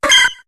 Cri de Germignon dans Pokémon HOME.